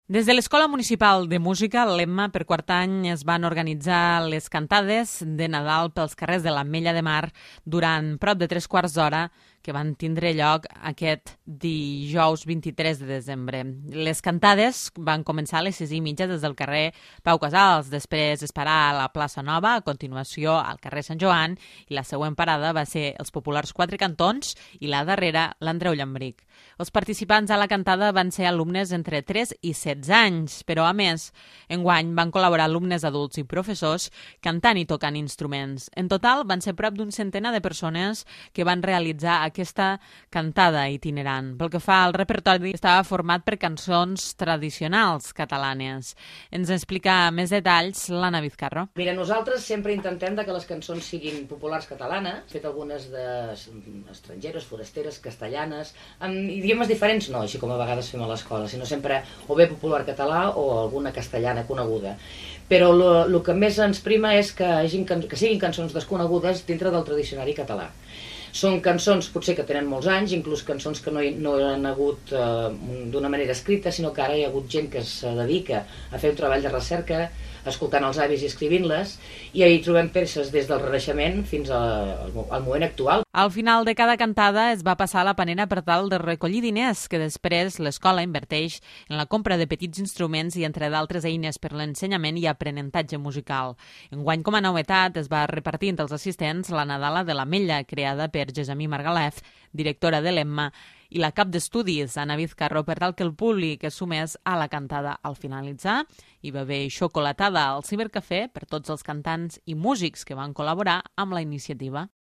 La Cantada de Nadales organitzada per l'EMMA omple d'esperit nadalenc els carrers de l'Ametlla
Enguany la cantada ha complit quatre anys i ha agrupat fins a prop de 100 participants.